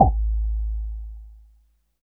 78 MOD SNARE.wav